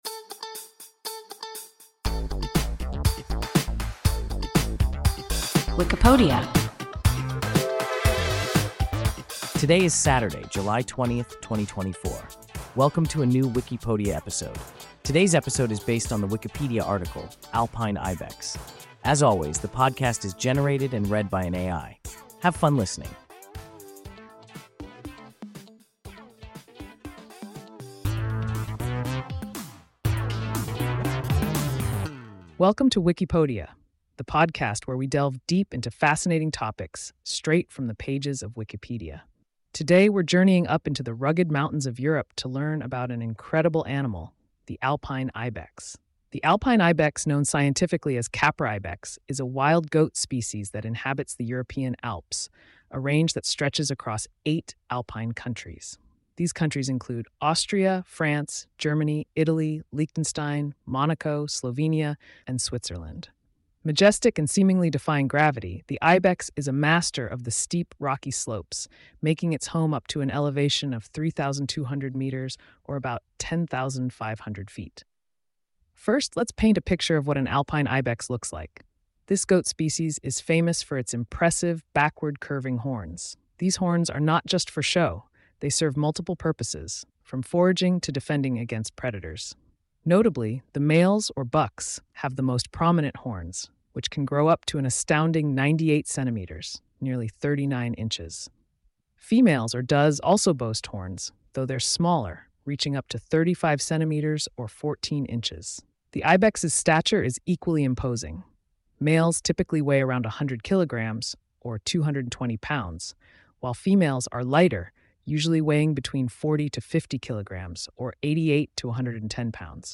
Alpine ibex – WIKIPODIA – ein KI Podcast